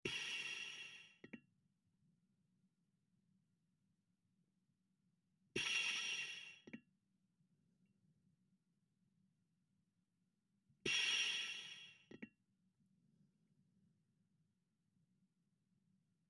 На этой странице собраны редкие звуки скафандра: от скрипа механизмов до шумов системы жизнеобеспечения.
Звуковой эффект скафандра